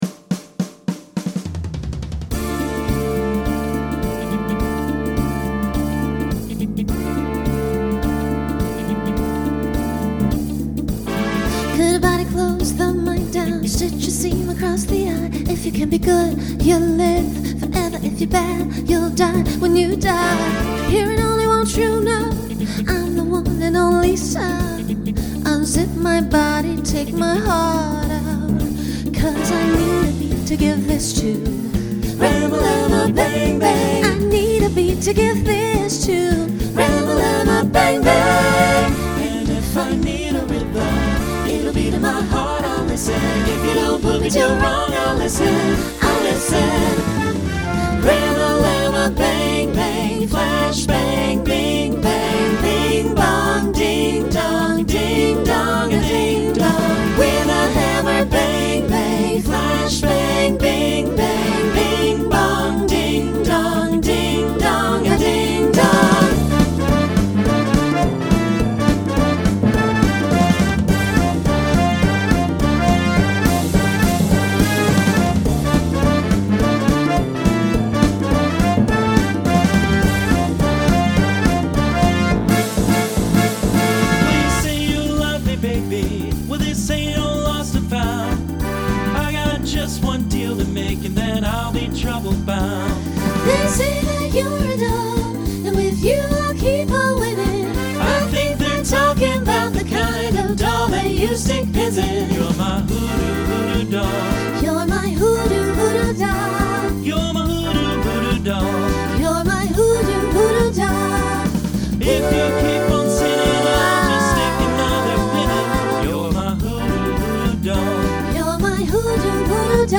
Genre Swing/Jazz Instrumental combo
Voicing SATB